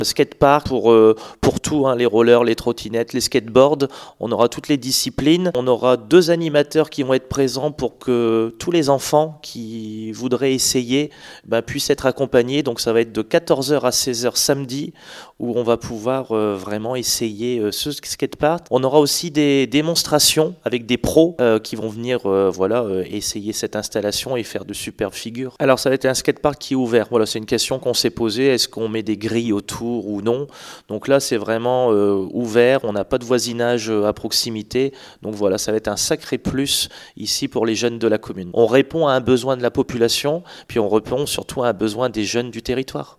Un équipement flambant neuf et voulu par la municipalité . volume-du-haut-parleur-icone-psd_30-2620Ecoutez Benoit ROUSSEL Maire de la ville
Benoit ROUSSEL – Maire de la ville d’ Arques